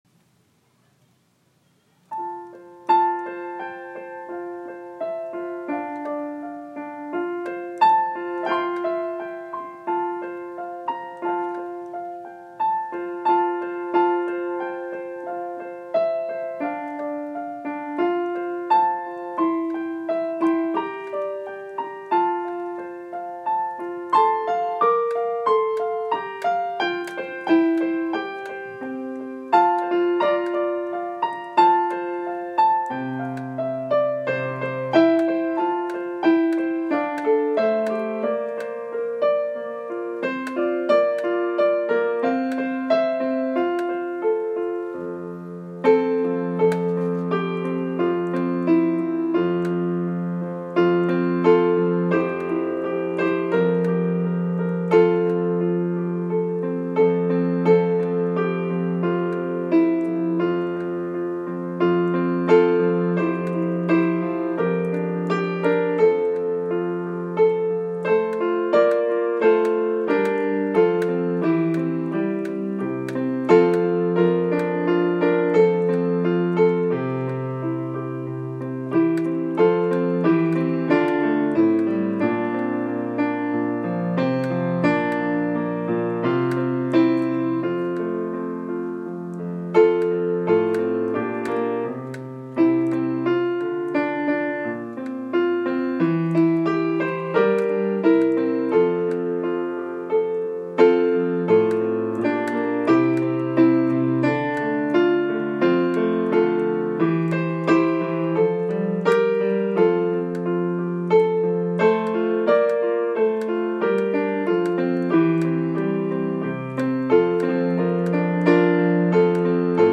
Den-blomstertid-pianobakgrund_2021.m4a